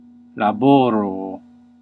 Ääntäminen
UK : IPA : /ˈwɜːk/ US : IPA : /ˈwɝk/